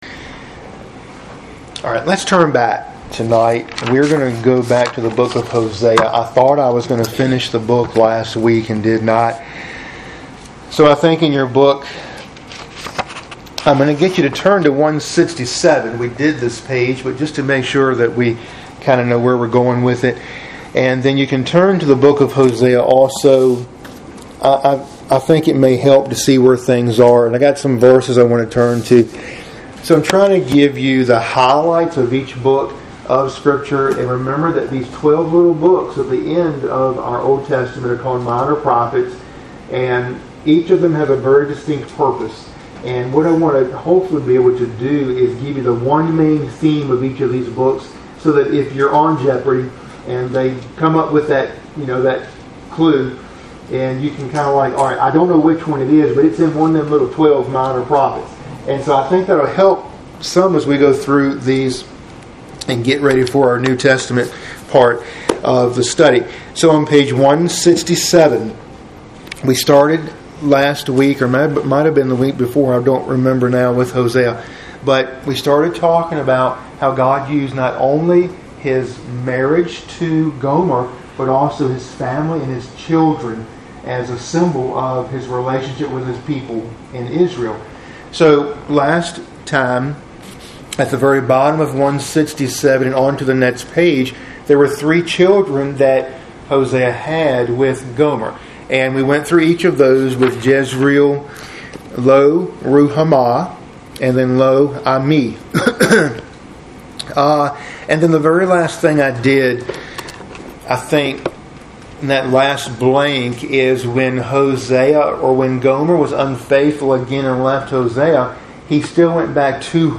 Midweek Bible Study – Lesson 34